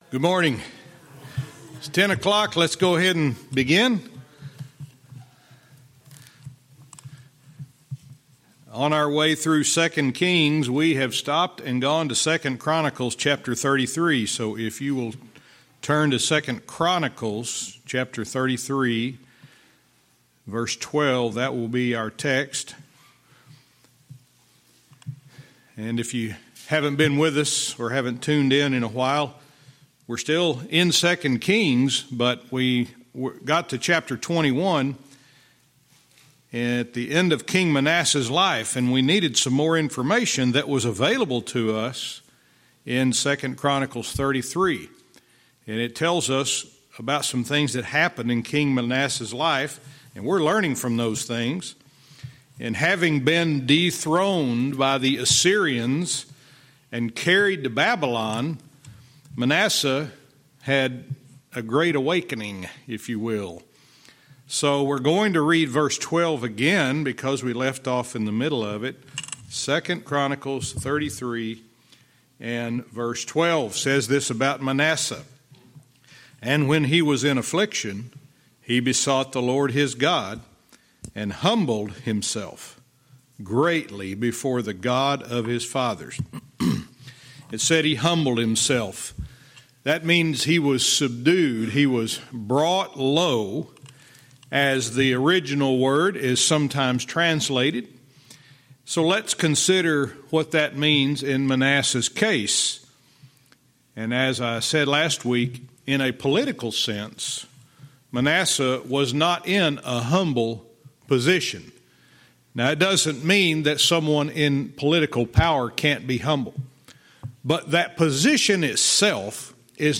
Verse by verse teaching - 2 Kings 21:18(cont) & 2 Chronicles 33:12-14